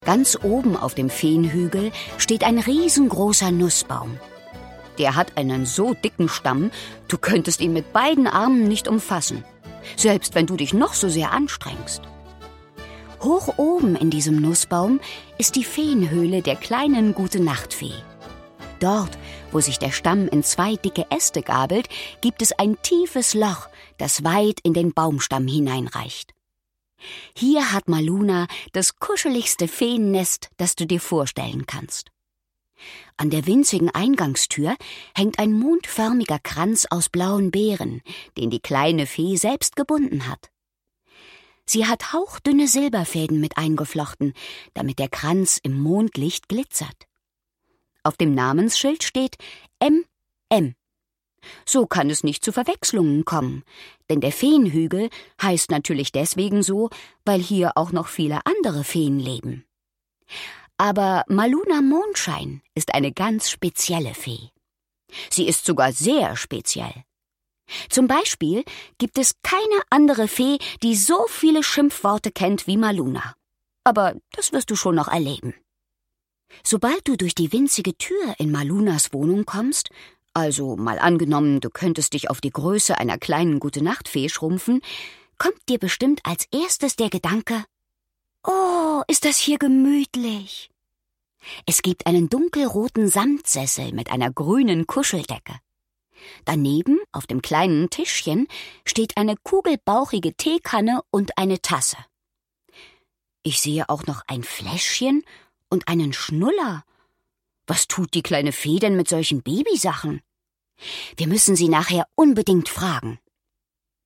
Hörbuch: Maluna Mondschein.